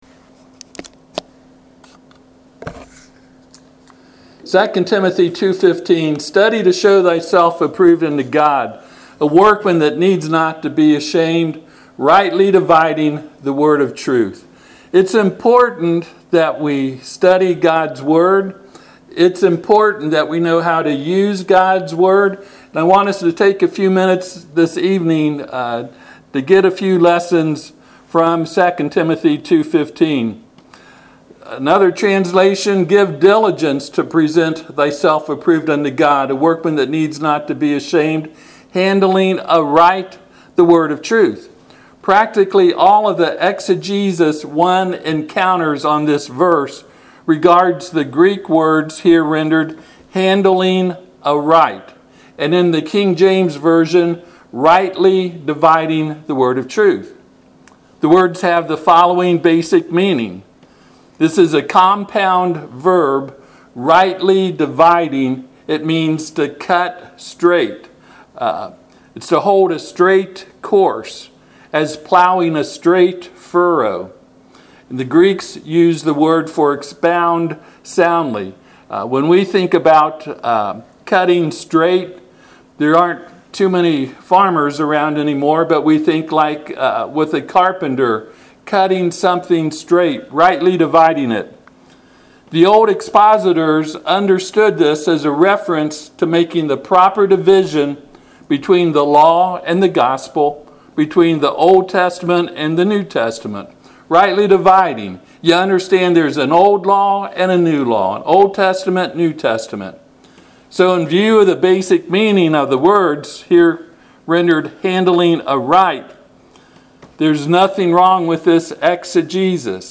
Service Type: Sunday PM